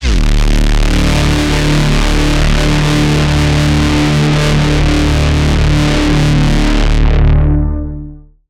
damage.wav